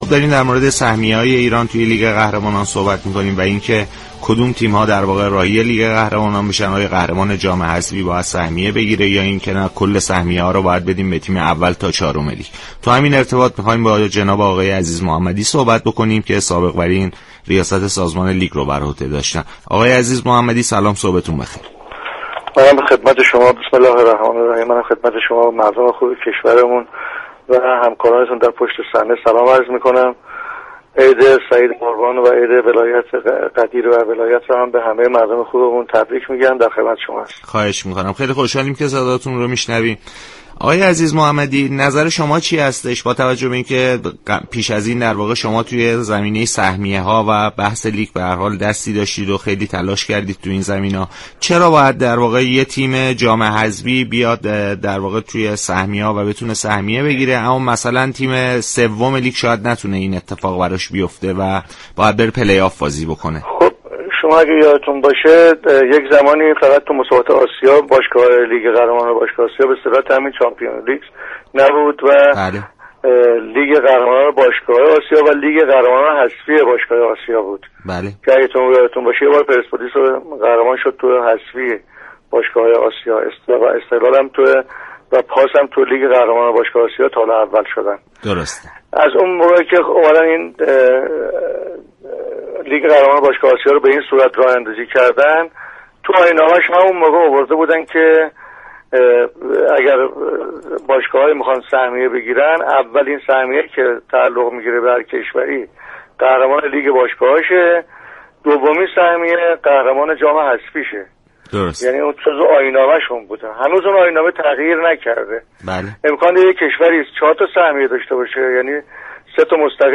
برنامه «صبح و ورزش» شنبه 11 مرداد در گفتگو با